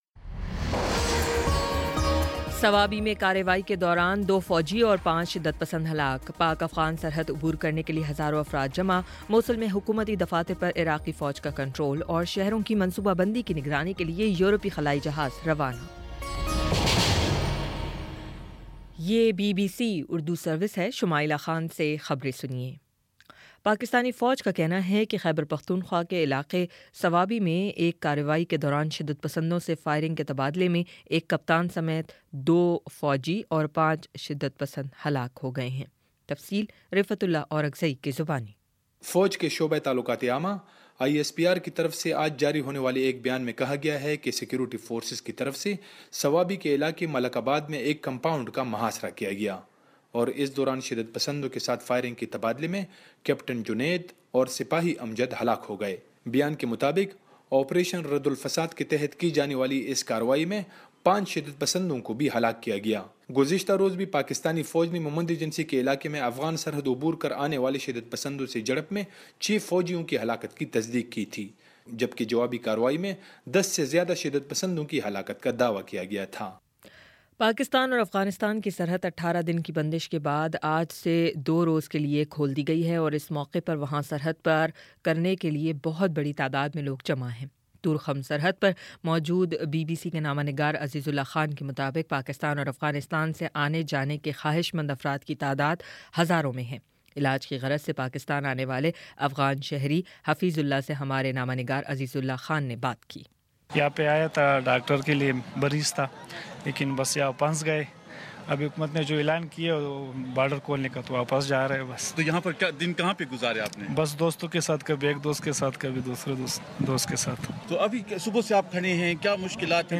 مارچ 07 : شام پانچ بجے کا نیوز بُلیٹن